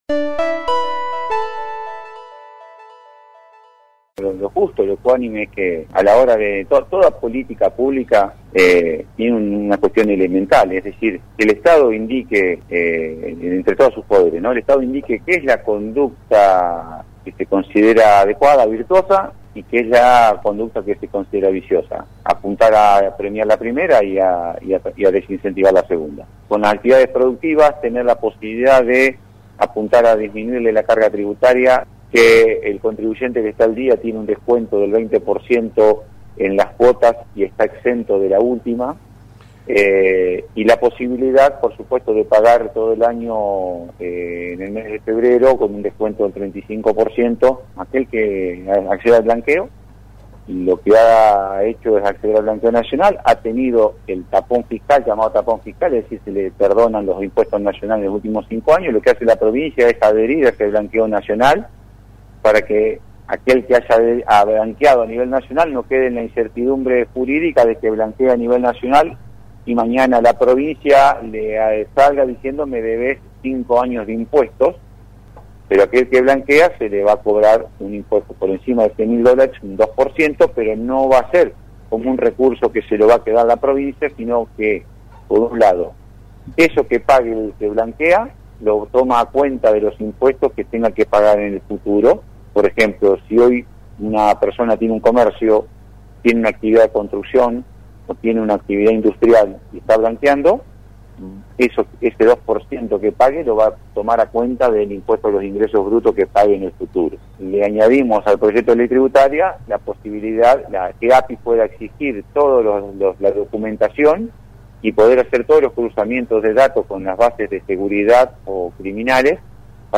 En contacto con LT3 el Ministro de Economía de la provincia, Pablo Olivares, anticipó que se cobrará un impuesto a quienes blanquearon dólares.